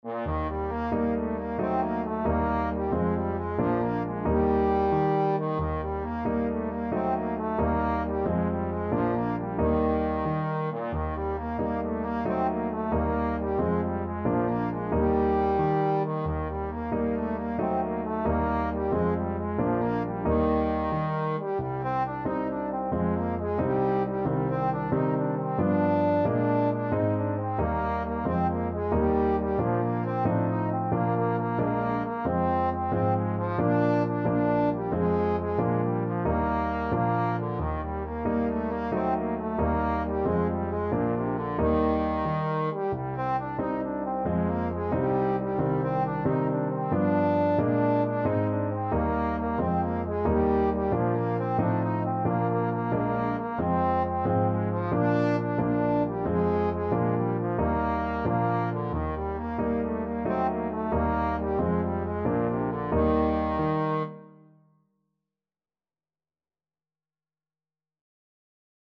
6/8 (View more 6/8 Music)
Moderato . = 90
Bb3-Eb5
Traditional (View more Traditional Trombone Music)